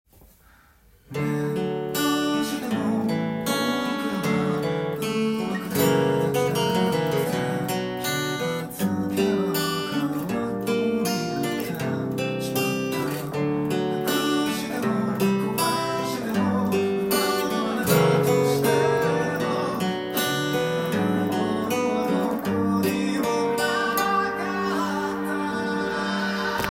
音源にあわせて譜面通り弾いてみました
冒頭のアルペジオtab譜になります。
譜面は2カポになっています。